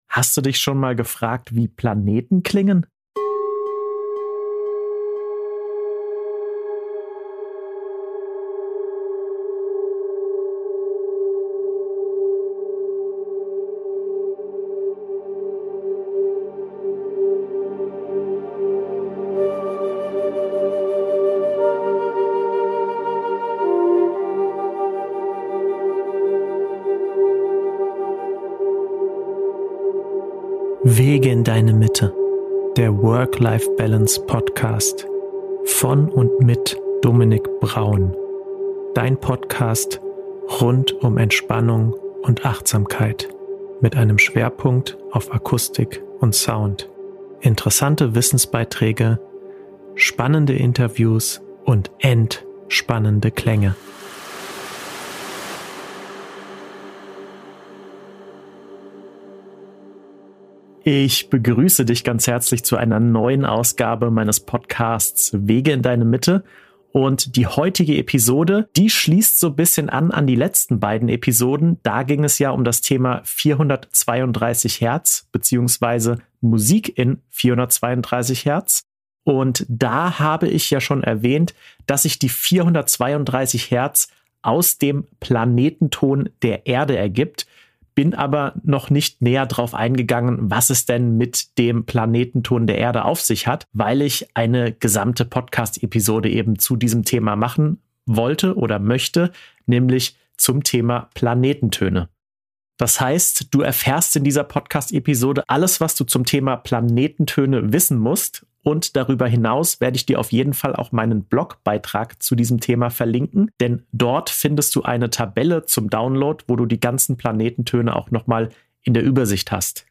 Ich gehe der Frage nach, was die Planetentöne überhaupt sind und wie man sie berechnet. Und natürlich zeige ich Dir, wie diese kosmischen Frequenzen klingen, indem ich Dir zahlreiche Klang-Beispiele vorspiele.